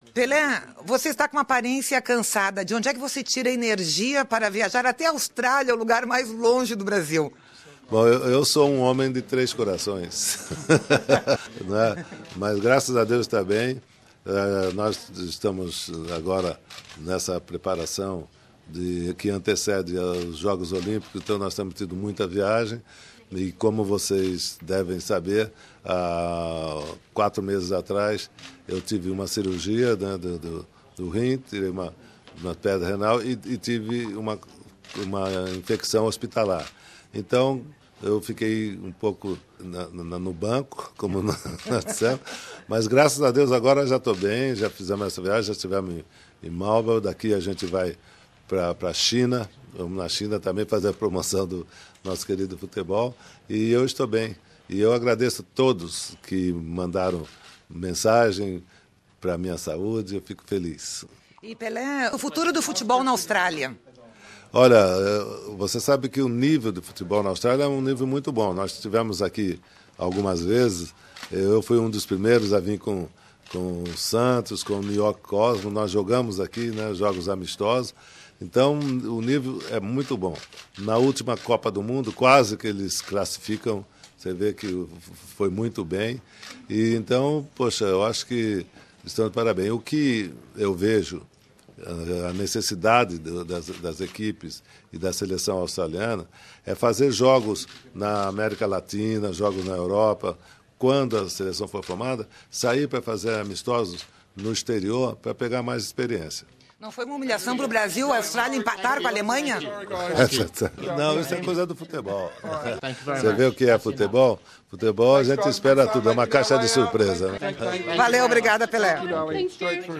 Na coletiva à imprensa do Rei Pelé nesta sexta-feira, 27 de março, em Sydney
Ouça aqui a entrevista em português.